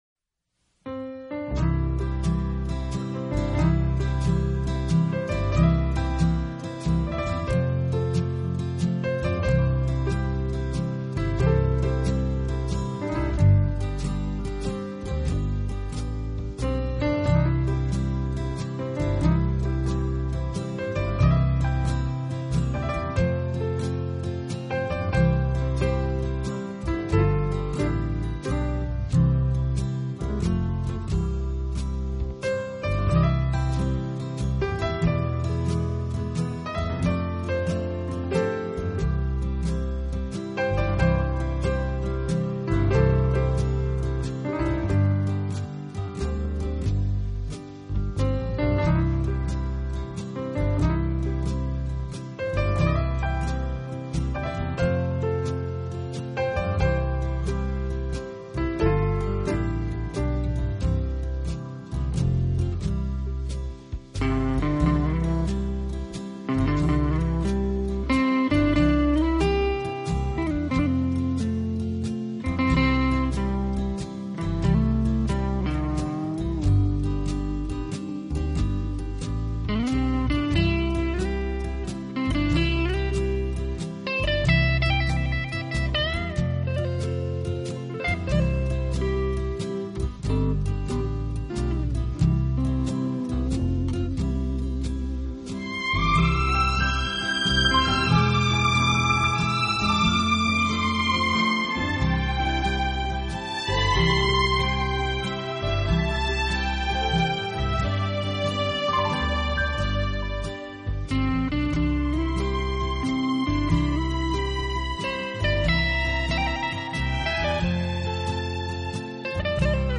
专辑歌手：纯音乐
如浪潮澎湃；史上最浪漫、最优美钢琴名曲，超时空经典，超想像完美。